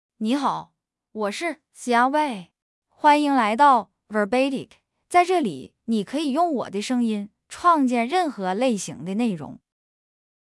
FemaleChinese (Northeastern Mandarin, Simplified)
Xiaobei — Female Chinese AI voice
Voice sample
Listen to Xiaobei's female Chinese voice.